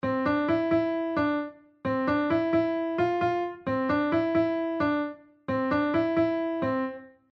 メロディーのコール＆レスポンス
この例では、4回目の最後の2音がE⇒Cと下がっているので、2回目の最後は逆にE⇒Fと上げてみました。
最初は「シンプルなモチーフを4回繰り返すだけ」だったけど、ここまで来ると物語性のあるメロディーになりましたね。
melody3.mp3